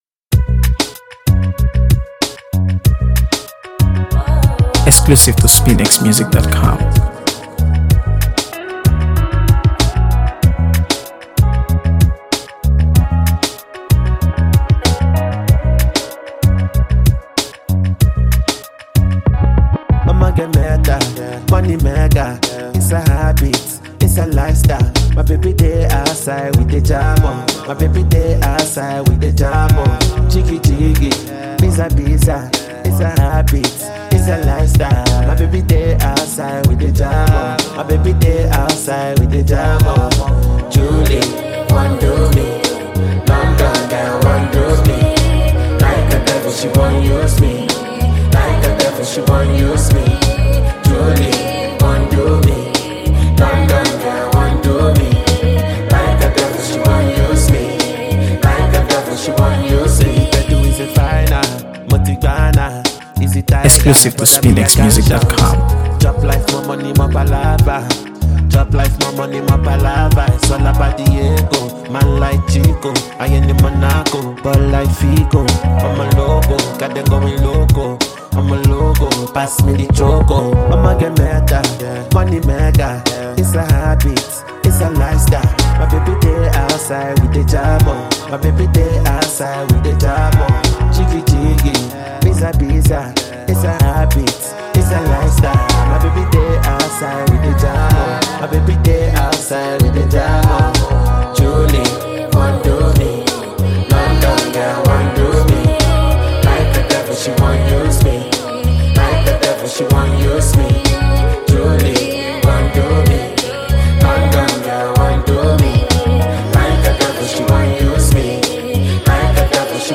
AfroBeats | AfroBeats songs
It’s bold, seductive